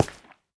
minecraft / sounds / step / coral4.ogg
coral4.ogg